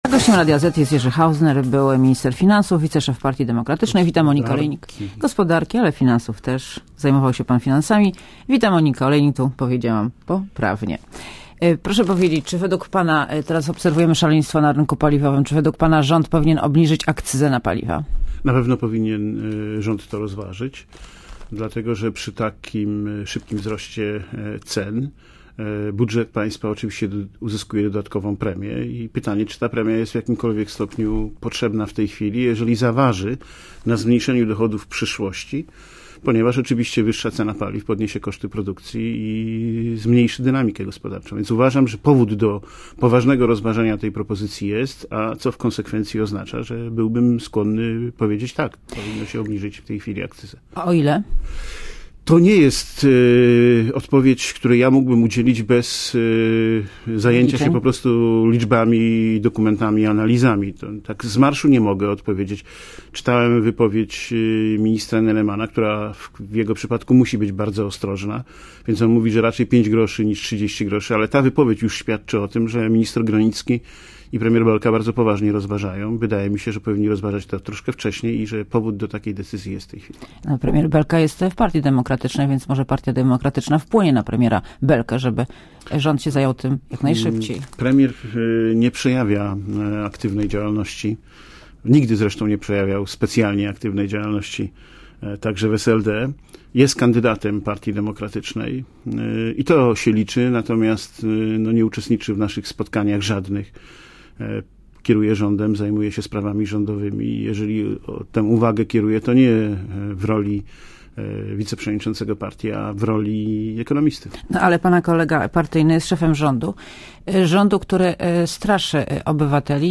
Gościem Radia ZET jest Jerzy Hausner, były minister gospodarki, wiceszef Partii Demokratycznej. Wita Monika Olejnik.